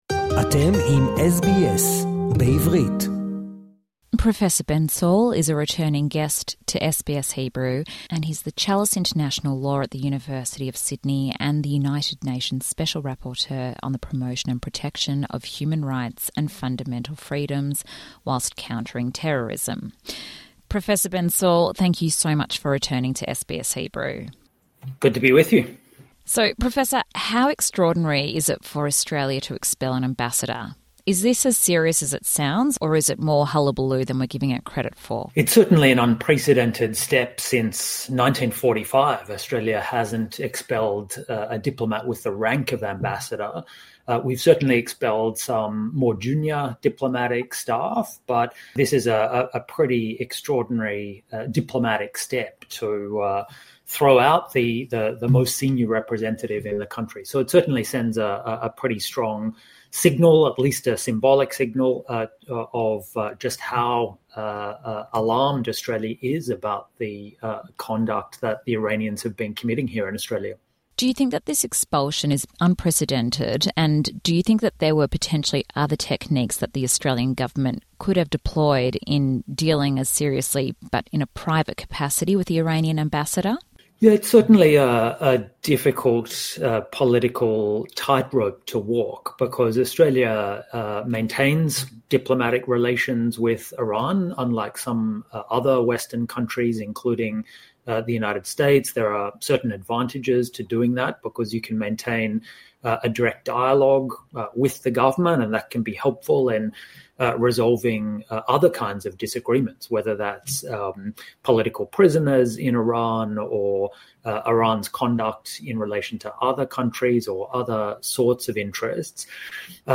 In a conversation with SBS Hebrew, Ben Saul University of Sydney professor and UN Special Rapporteur analyses Australia’s unprecedented expulsion of Iran’s ambassador. He explains the move as a "strong symbolic signal" against Iran's destabilising activities on Australian soil, which endanger its multicultural pluralism. Professor Saul also explores the complex implications of potentially listing Iran’s Revolutionary Guards as a terrorist organisation.